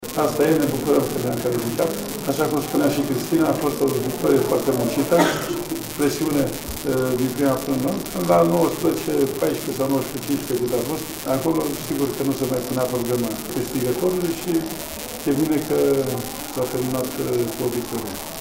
Ascultaţi  trei declaraţii „la cald”: căpitanul Aurelia Brădeanu, golgheterul Cristina Neagu şi antrenorul Gheorghe Tadici